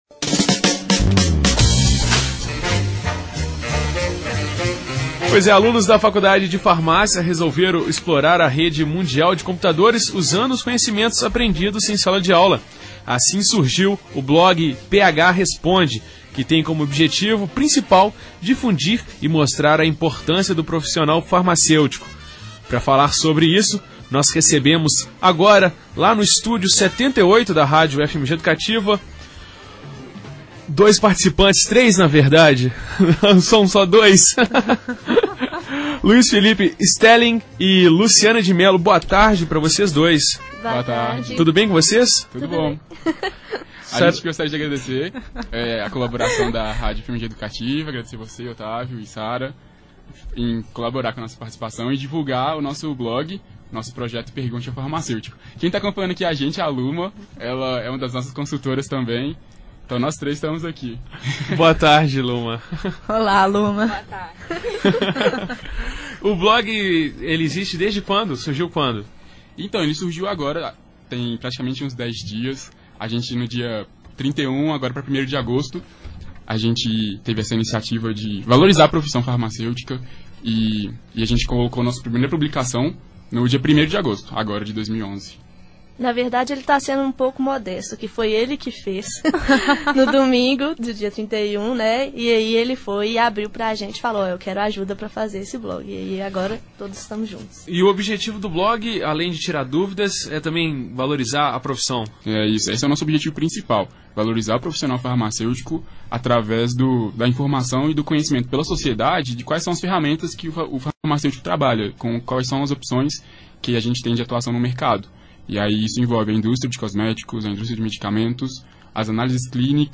Ouça a Entrevista da Equipe do "Pergunte ao Farmacêutico" na Rádio UFMG Educativa
Está disponível na página do Expresso 104,5, programa da Rádio UFMG Educativa, a íntegra da entrevista da equipe do "Pergunte ao Farmacêutico". Se você perdeu a entrevista ao vivo na última semana, não deixe de conferir agora!